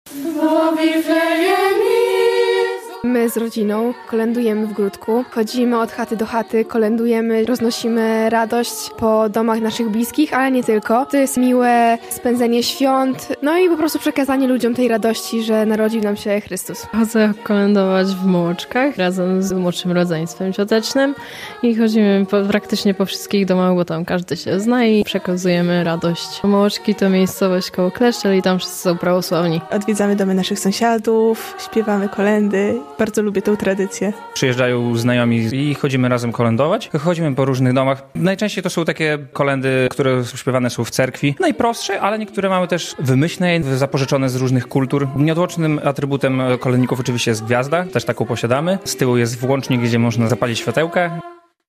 Prawosławni kolędują w Boże Narodzenie - relacja